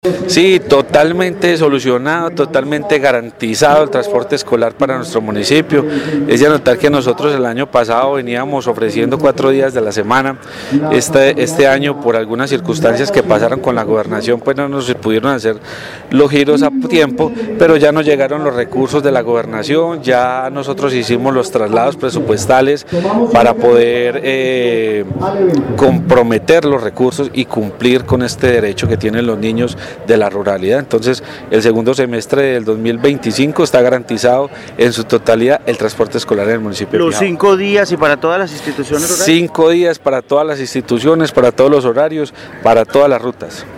Jhon Jairo Restrepo, alcalde de Pijao